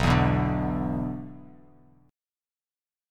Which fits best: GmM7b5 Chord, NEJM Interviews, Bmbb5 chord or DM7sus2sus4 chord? Bmbb5 chord